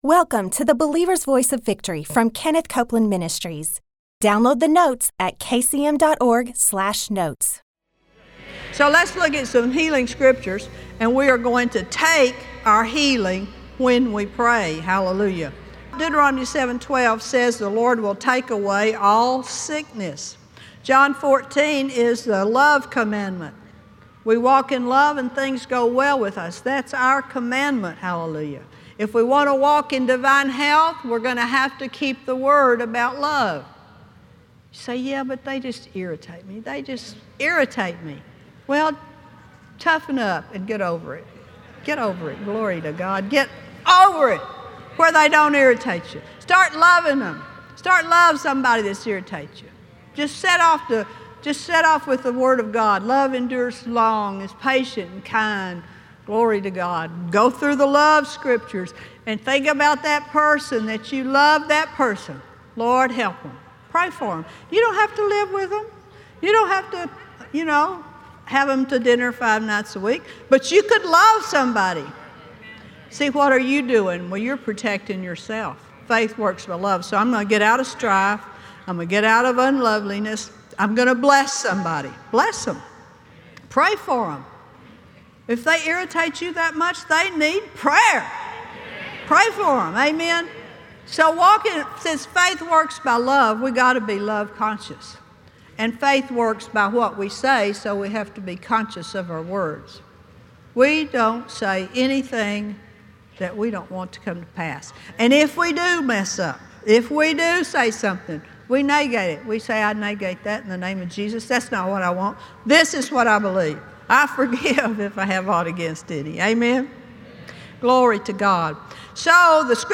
Believers Voice of Victory Audio Broadcast for Thursday 12/17/2015On today’s Believer’s Voice of Victory, Kenneth and Gloria Copeland teach you how to stand against the enemy, and open the door to freedom for your total healing from sickness, pain, fear, and grief. Through Christ, you have the power to live free!